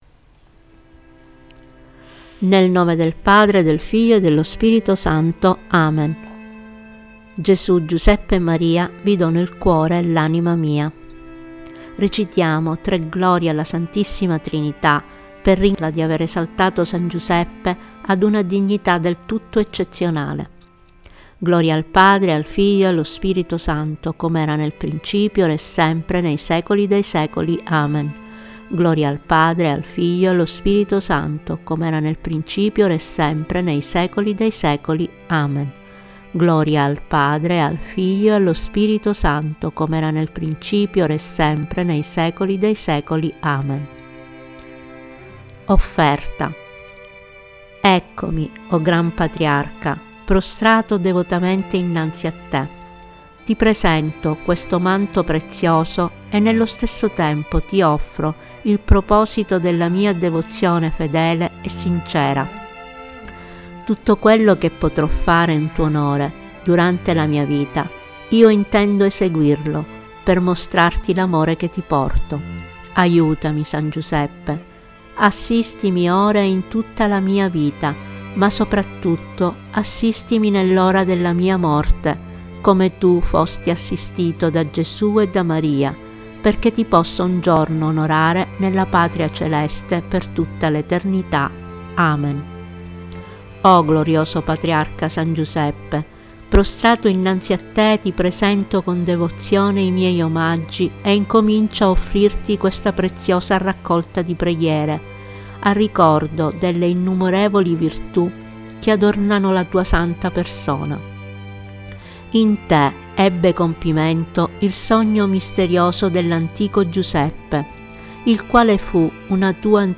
Sacro-Manto-di-S.-Giuseppe---Tempo-di-preghiera-musica.mp3